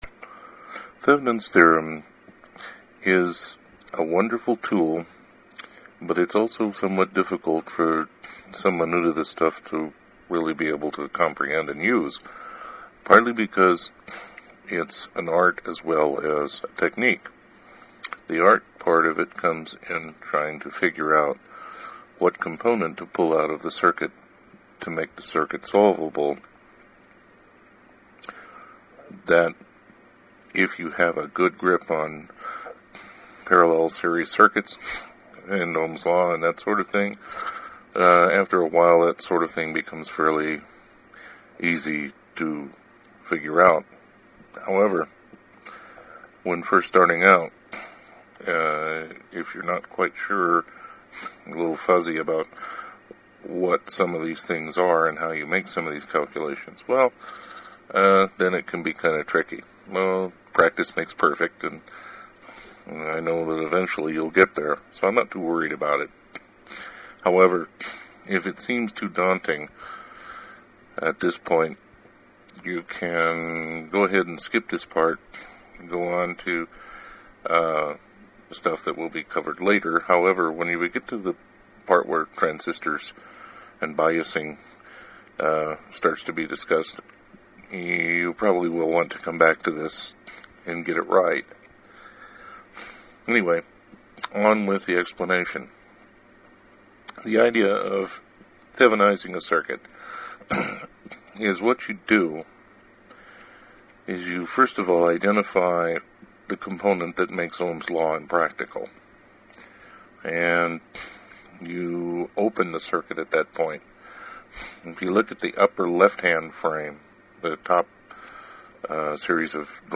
Audio discussion